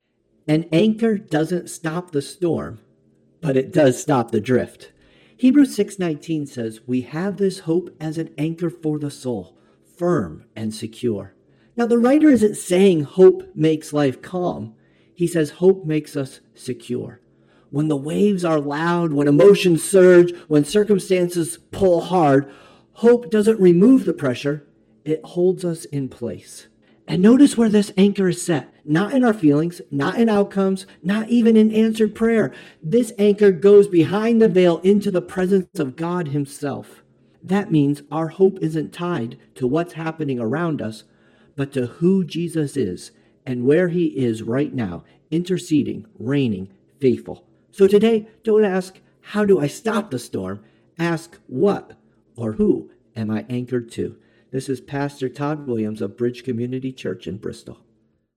One Minute inspirational thoughts presented by various clergy!